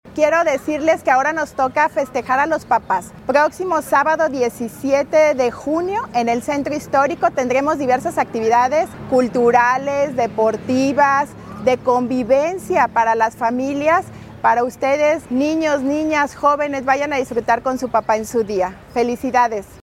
AudioBoletines
Lorena Alfaro García, presidenta municipal, invitó a las familias irapuatenses a disfrutar actividades culturales, musicales y deportivas que se realizarán en el Andador Sor Juana Inés de la Cruz, Jardín Principal y el Andador Juárez, de 5:00 de la tarde a 9:00 de la noche.